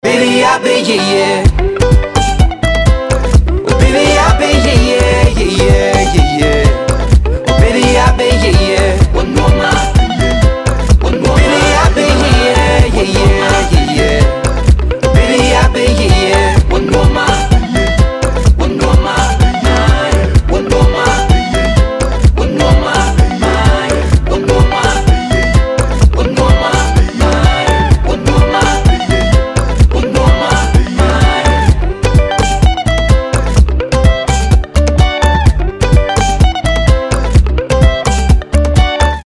• Качество: 320, Stereo
поп
позитивные
мужской вокал
зажигательные
dance
латина